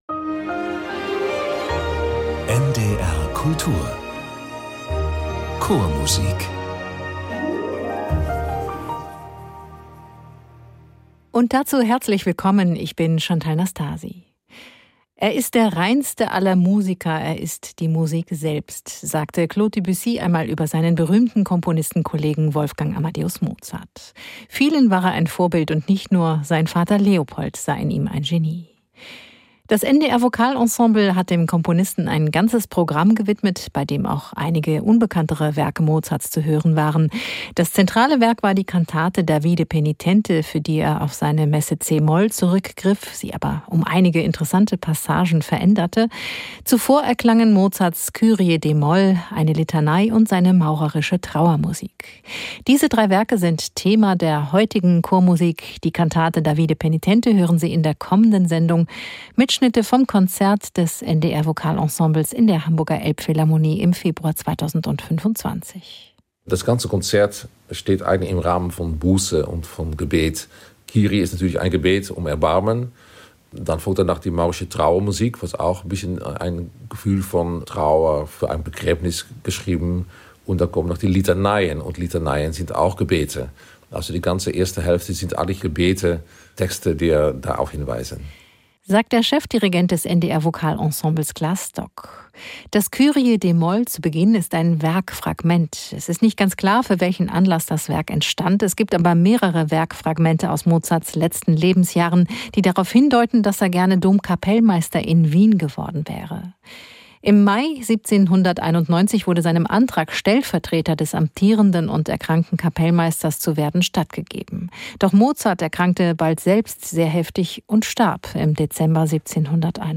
Drei bewegende Werke im Mozart-Programm unseres Chores mit der Kammerakademie Potsdam und herausragenden Solisten.